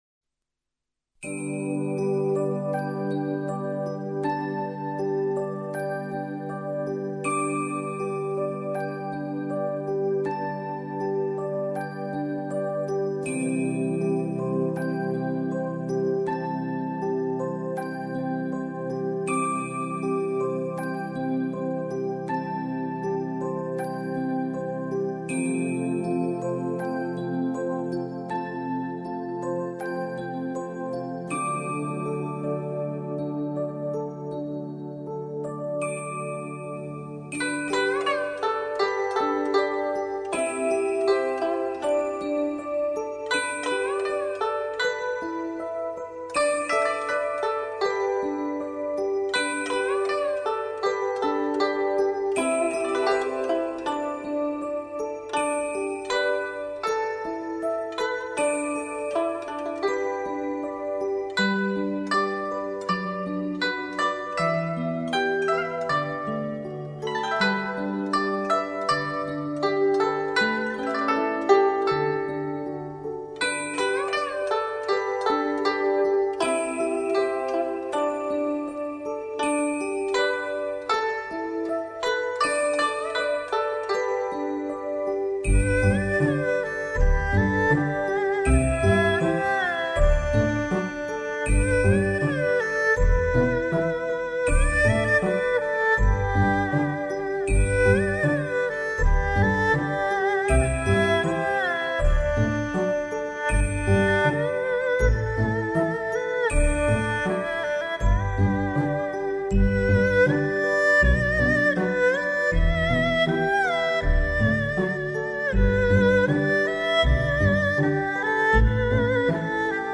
也是以古筝诠释台湾民谣的成功之作
运用乐器的丰富变化，创造了台湾民谣的不同韵味
☆古筝、月琴、竹笛结合台湾传统民谣，再创音乐演出新风貌
乐曲充满感情、气氛怀旧动人，帮助您打开尘封的记忆